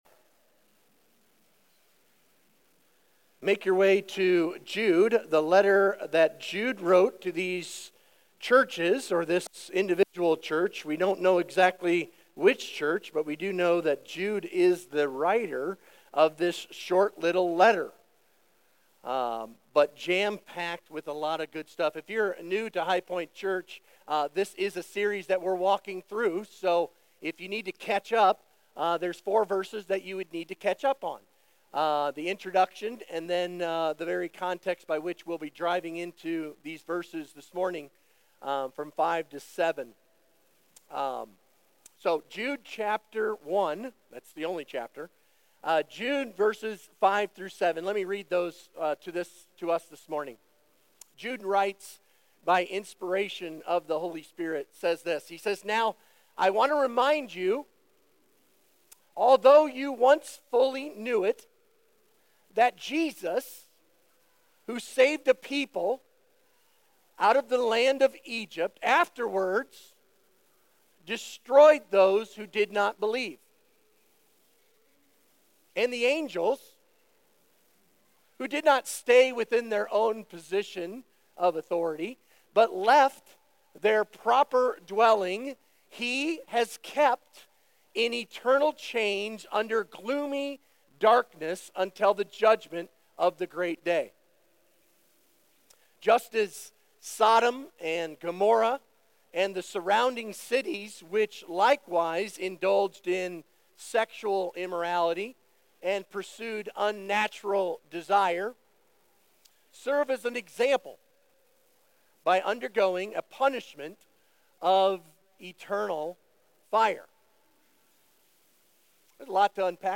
Sermon Questions Read Jude 1-7.